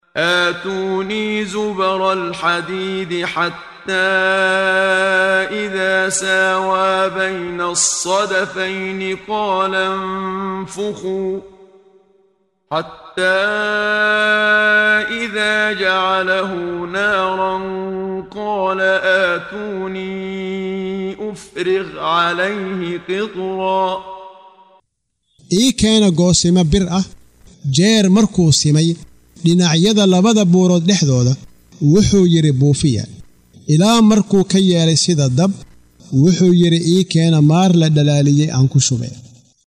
Waa Akhrin Codeed Af Soomaali ah ee Macaanida Suuradda Al-Kahaf ( Gebiga ) oo u kala Qaybsan Aayado ahaan ayna la Socoto Akhrinta Qaariga Sheekh Muxammad Siddiiq Al-Manshaawi.